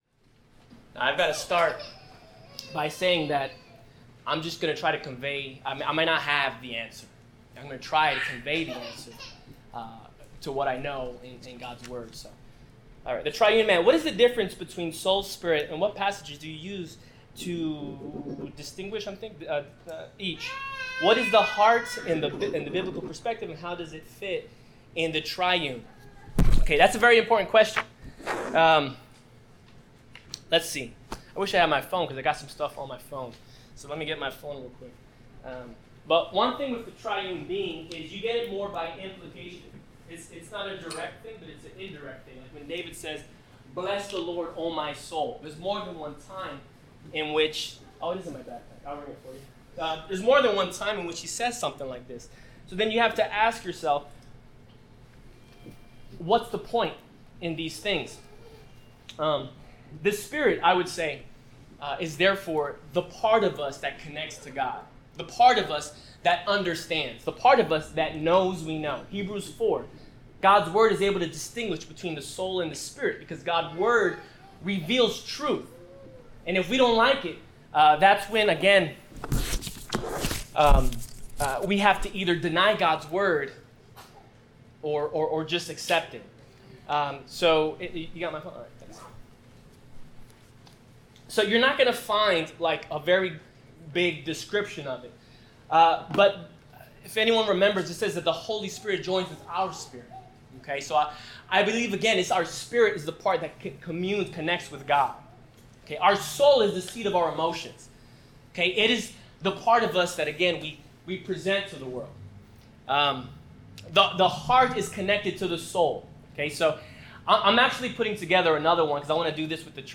God of the Brokenhearted – Q & A